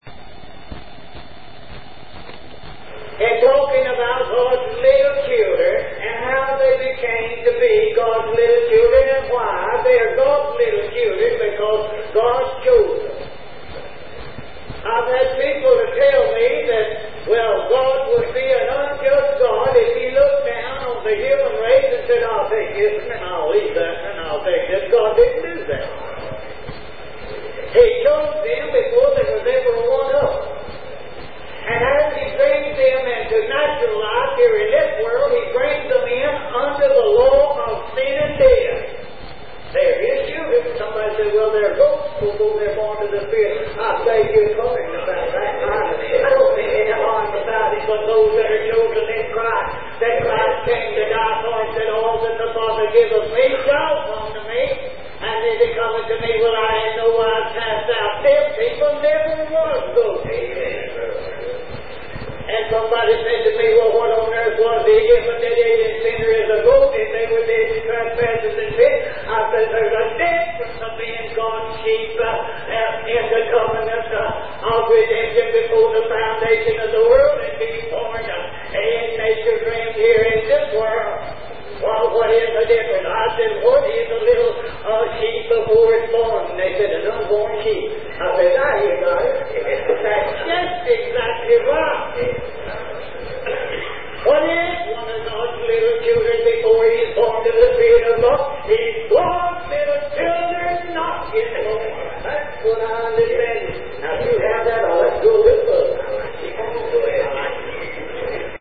in this 1982 sermon excerpt recorded at the 1982 session of the Wetumpka association